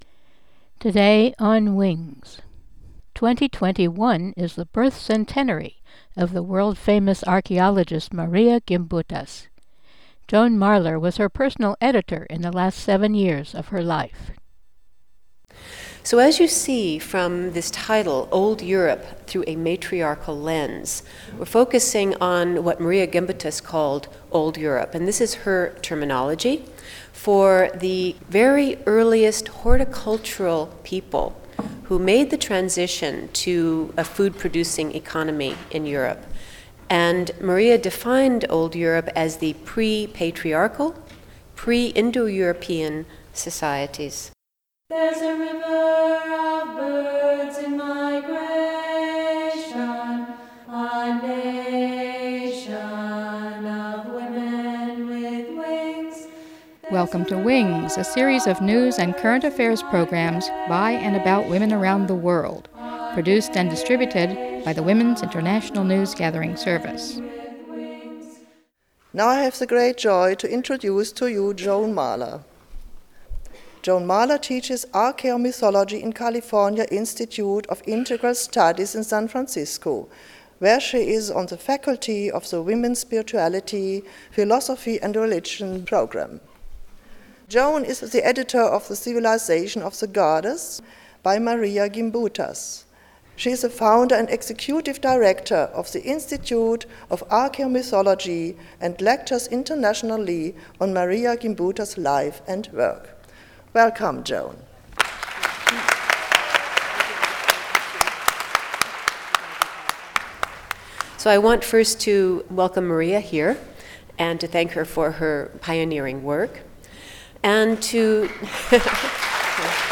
at 2nd World Congress of Matriarchal Studies